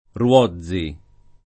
[ r U0zz i ]